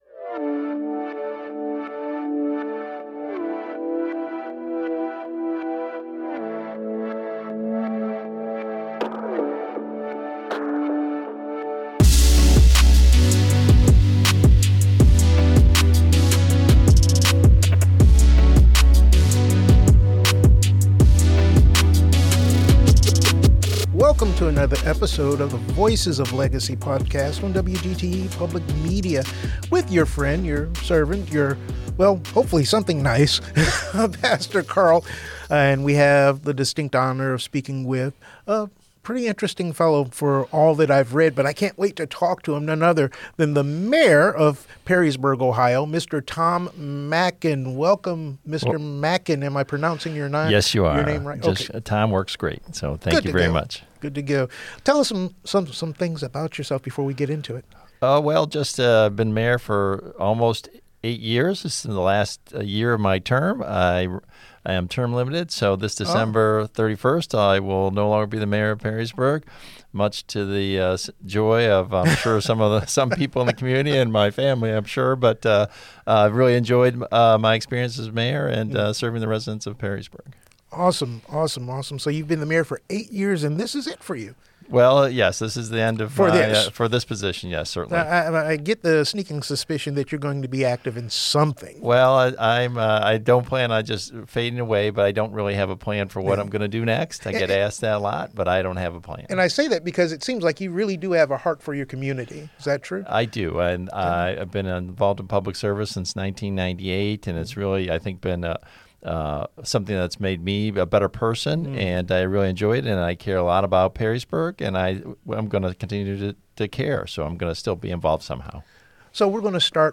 Leading Perrysburg: A Conversation with Mayor Tom Mackin - WGTE Public Media